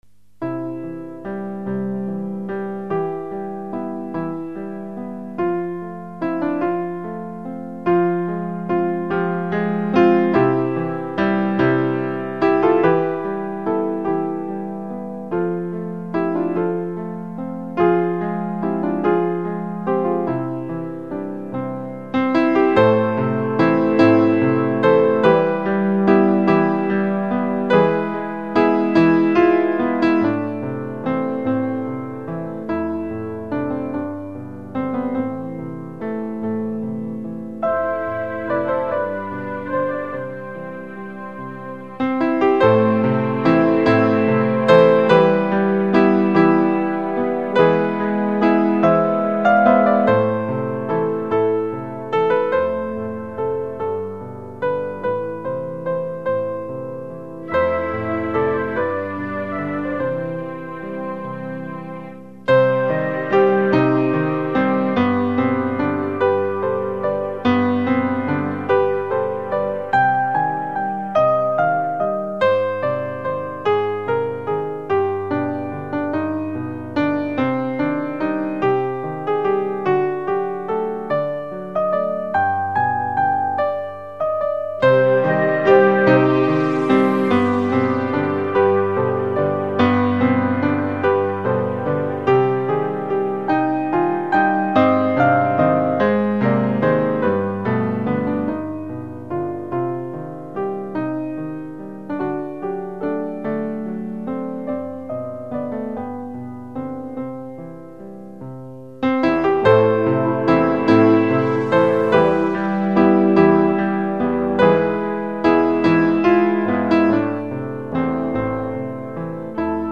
Lugn pianomusik.